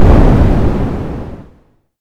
ctf_ranged_explode.ogg